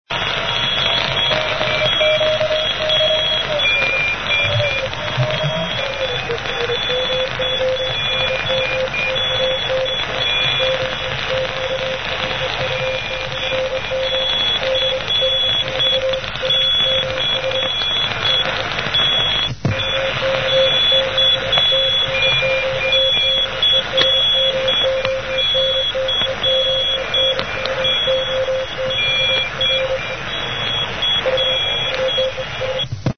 the telegraph marker from NMO